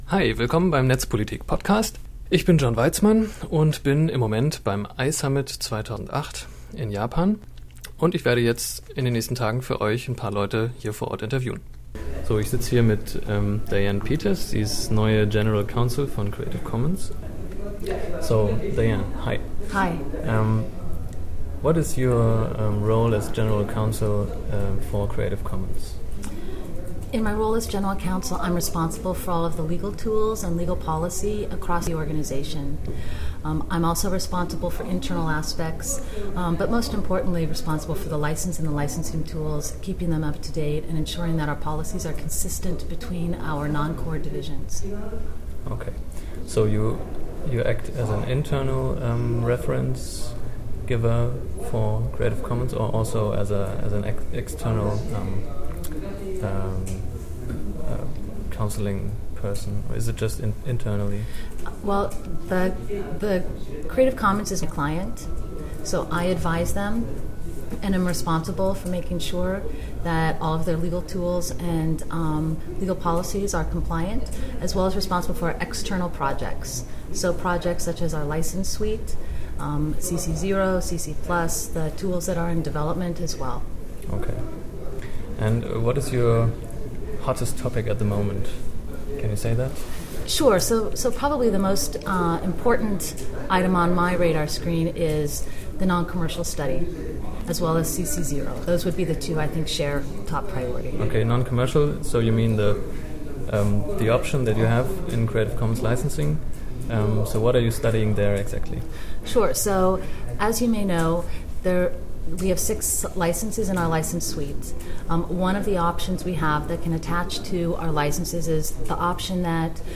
Das Interview ist ca. Minuten lang und liegt als MP3 und